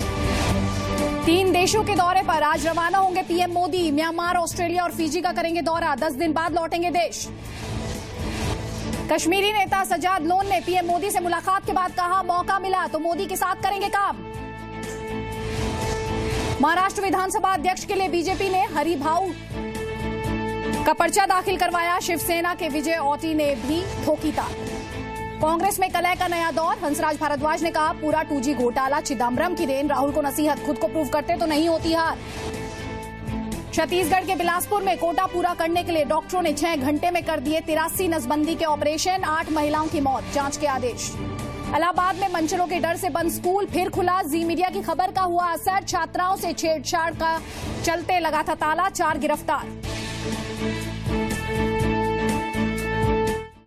Top news headlines of the day